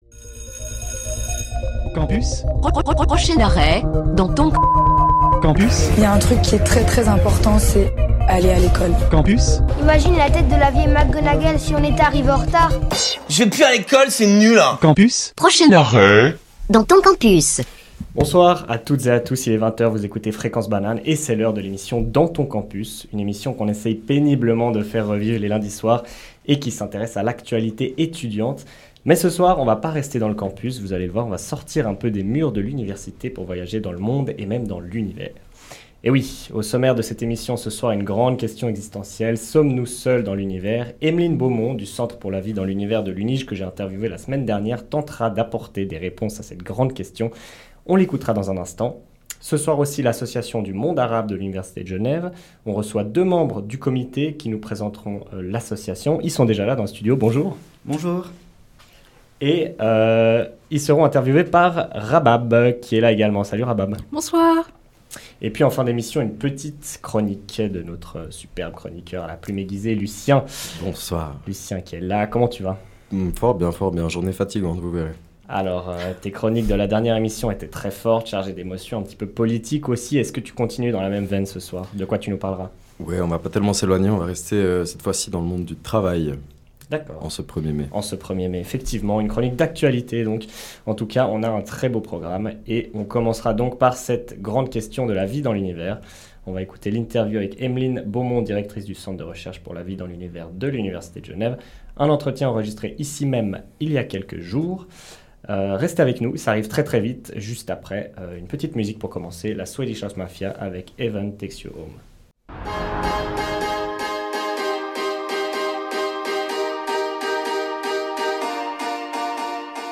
Interview
une chronique "en immersion" dans le cortège du 1er mai à Genève